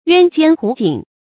鸢肩鹄颈 yuān jiān hú jǐng
鸢肩鹄颈发音
成语注音 ㄧㄨㄢ ㄐㄧㄢ ㄏㄨˊ ㄐㄧㄥˇ